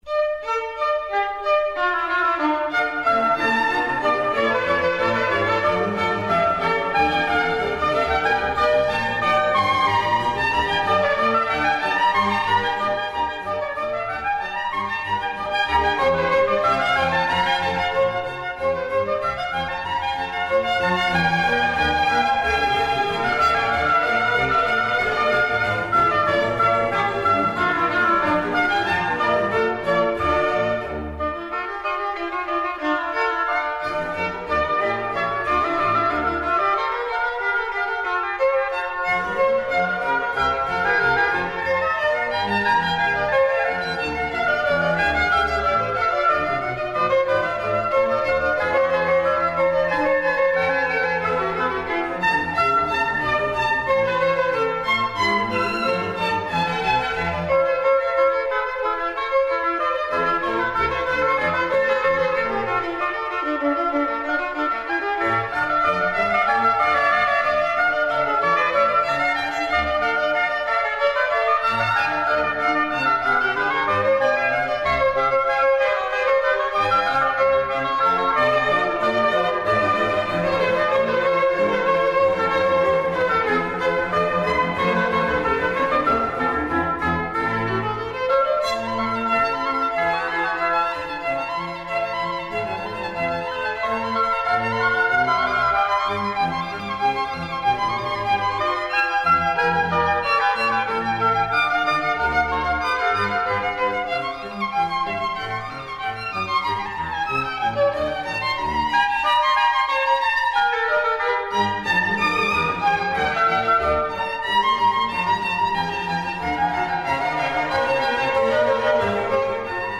Violin & Oboe
Allegro